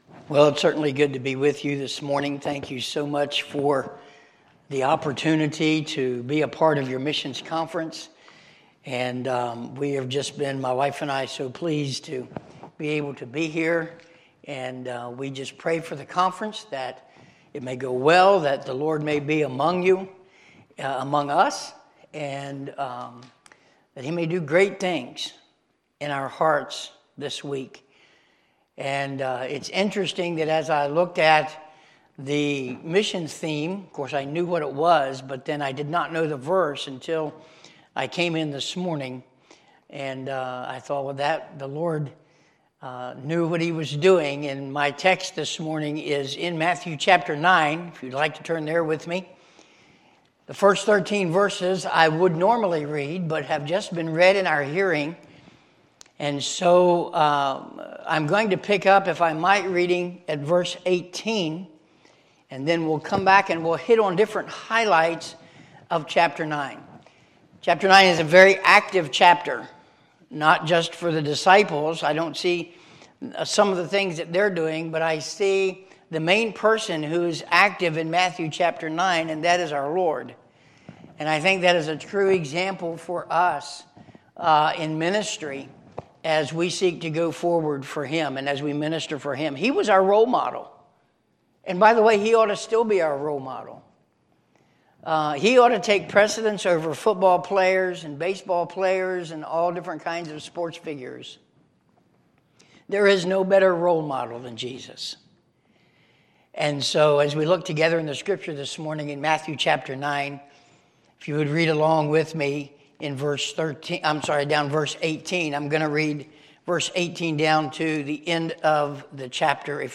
Sunday, September 17, 2023 – Sunday AM
Sermons